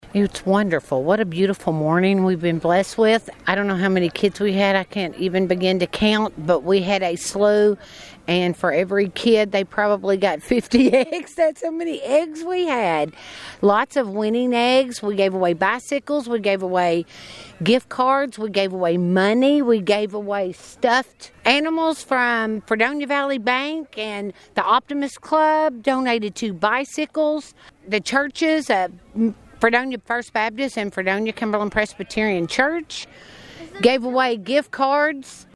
Children from around the area gathered at the Buddy Rogers Ballpark in Fredonia Saturday morning for the annual community Easter egg hunt.